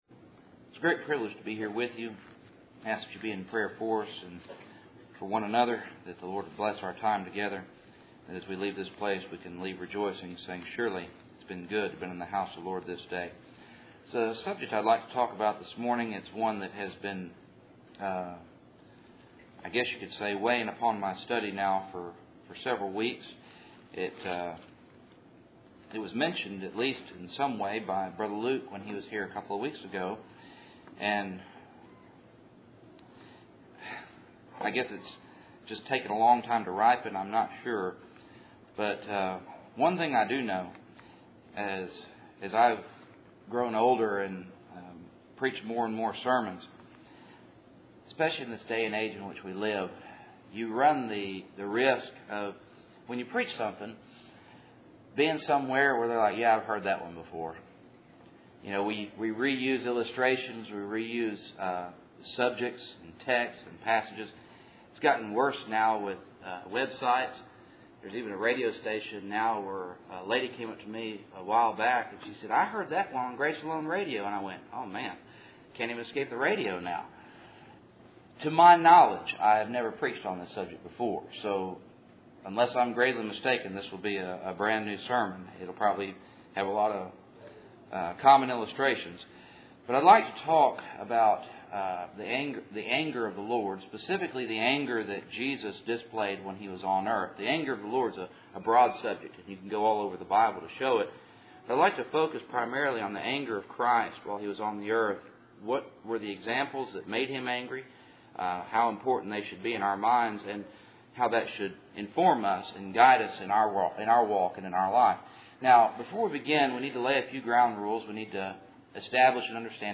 Service Type: Cool Springs PBC Sunday Morning